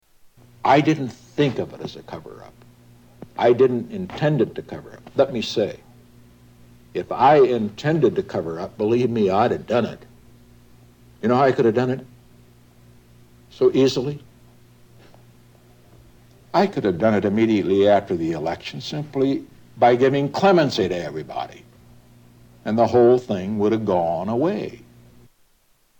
Tags: Historical Frost Nixon Interview Audio David Frost Interviews Richard Nixon Political